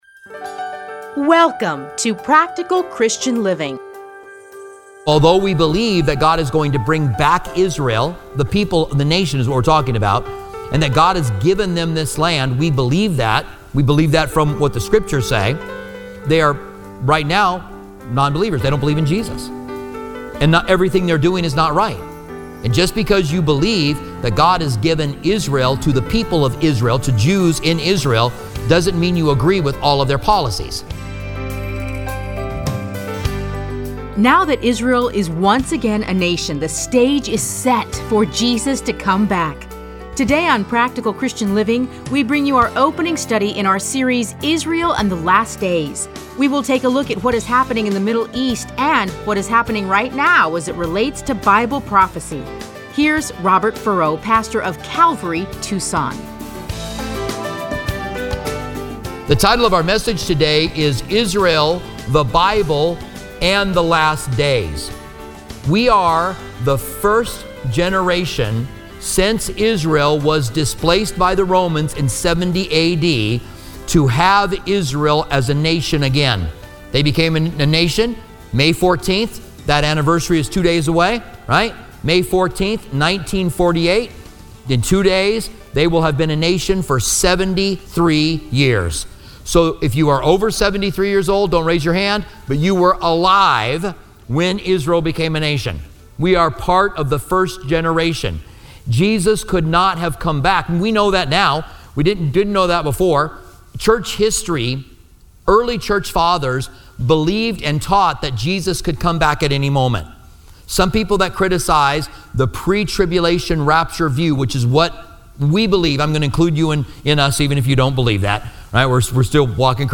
Listen to a teaching from selected passages.